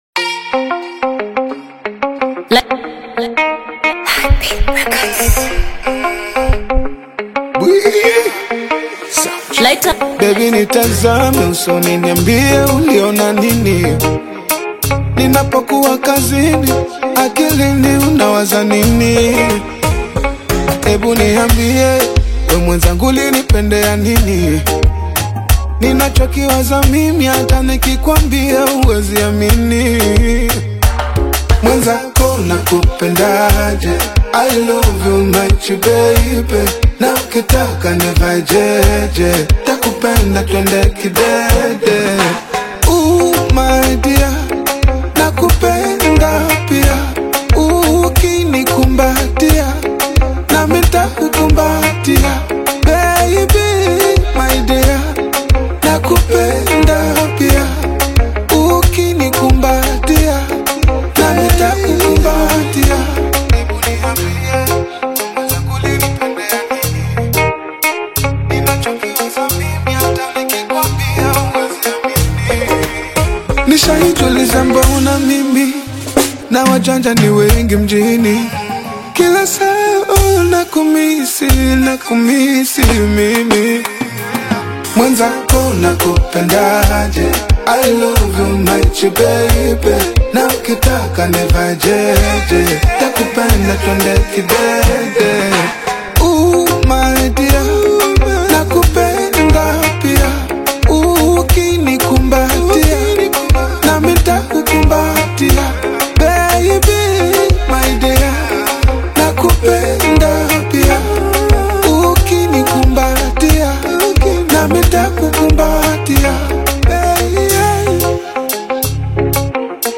soulful, R&B-infused track